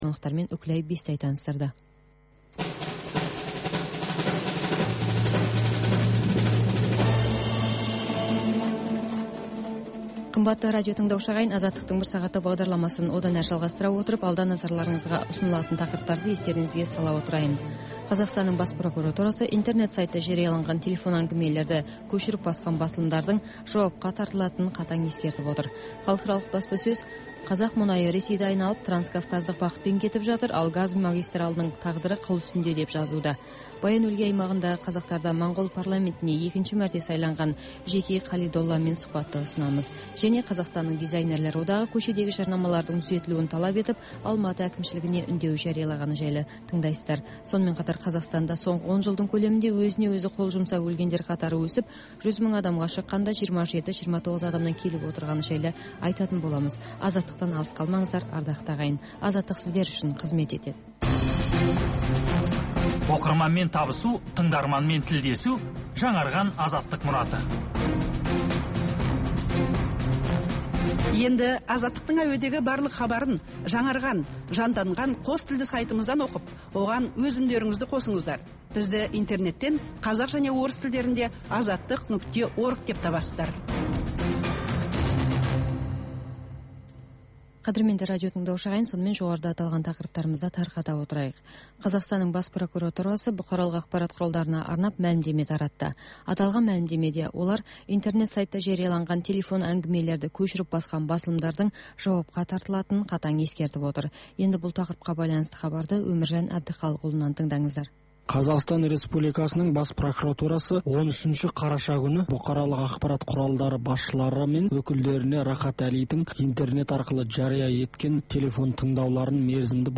Бүгінгі күннің өзекті тақырыбына талқылаулар, оқиға ортасынан алынған репортаж, пікірталас, қазақстандық және халықаралық сарапшылар пікірі.